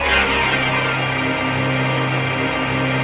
ATMOPAD21 -LR.wav